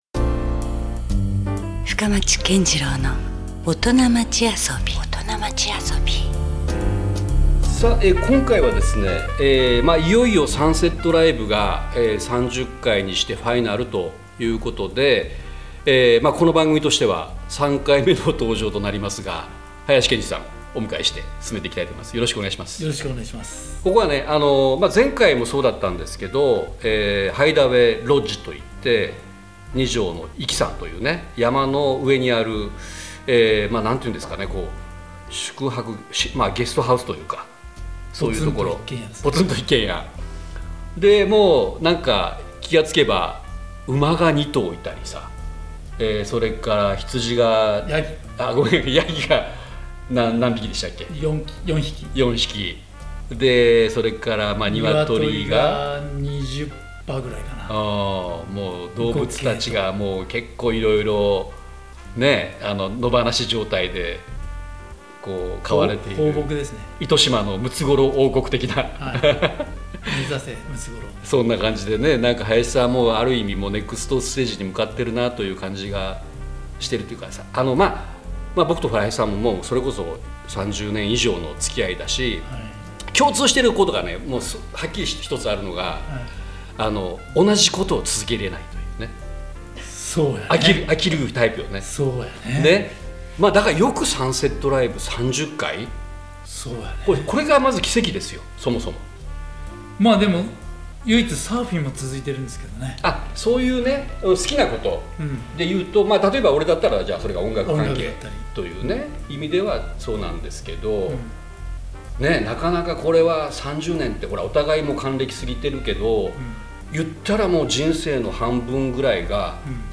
「革新的にシゴトをして、独創的にアソブ」そんなオトナをお迎えし、A面「シゴトへの姿勢」と、B面「アソビへのこだわり」についてお話を頂きます。各界でご活躍されているオトナのライフスタイルを通して、本当に”カッコイイオトナ”とは何なのかをフカボリしていきます。